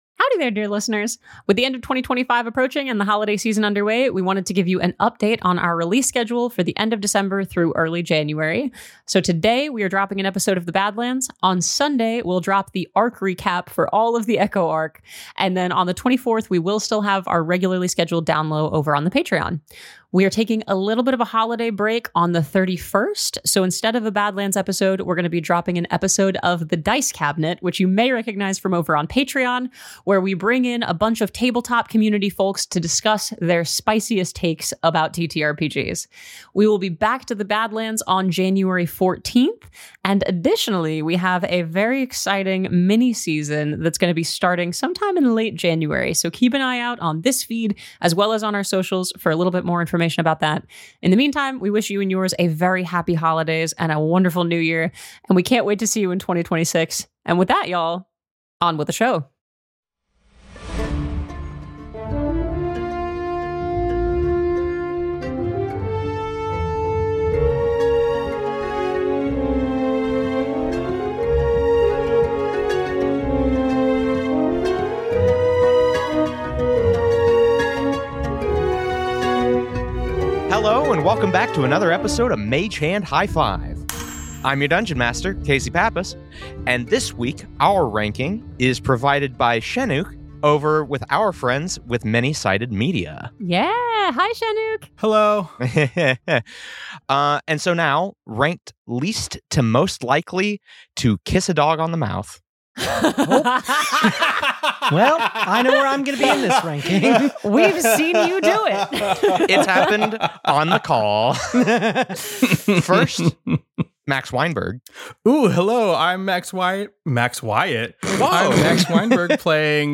CW: Gunshot, mention of child death and loss of a child, depression